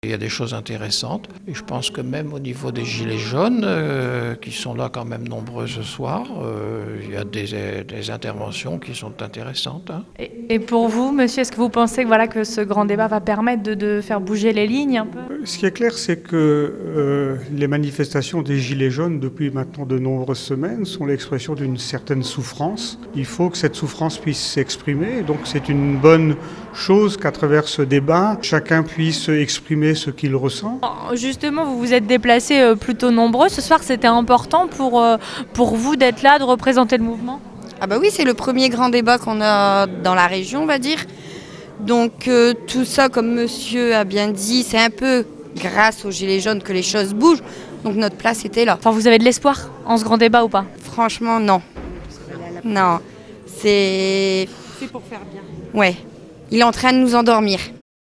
Près de 100 personnes et une dizaine de gilets jaunes ce lundi soir lors du Grand Debat organisé à Neufchatel Hardelot.